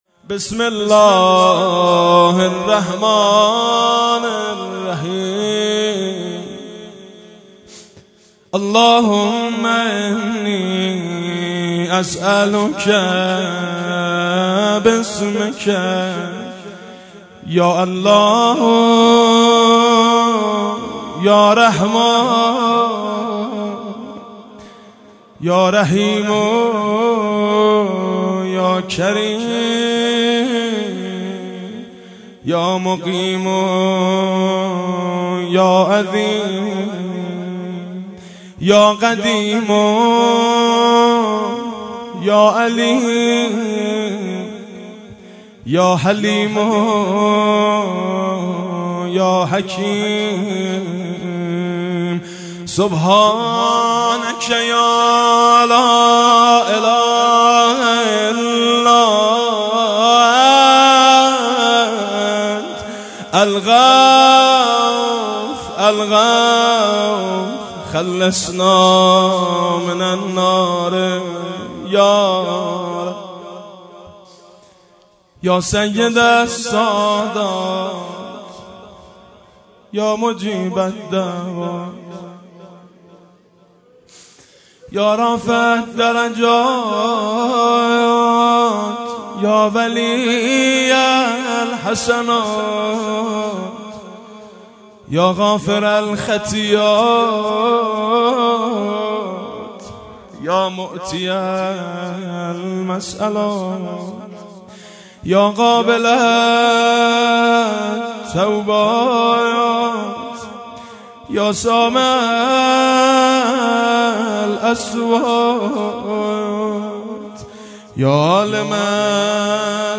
اعمال شب‌ قدر و صوت دعای جوشن کبیر - تسنیم